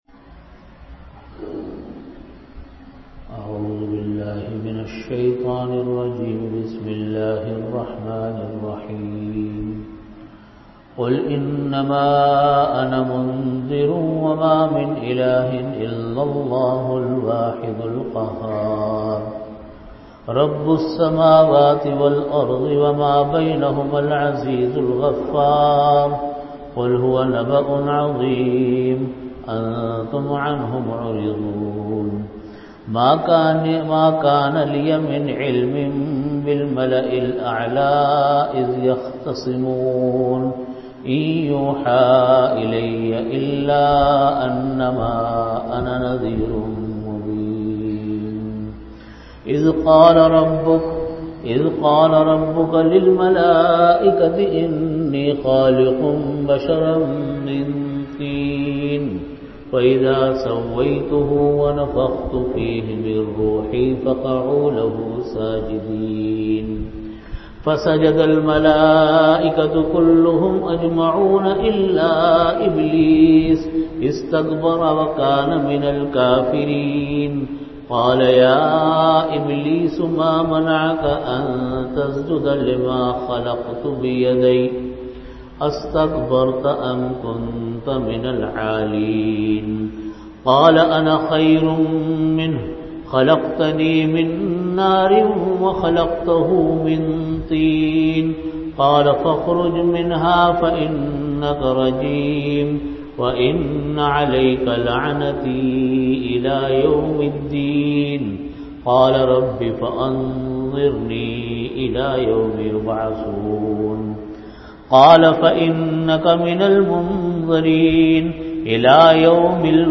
Audio Category: Tafseer
36min Time: After Asar Prayer Venue: Jamia Masjid Bait-ul-Mukkaram, Karachi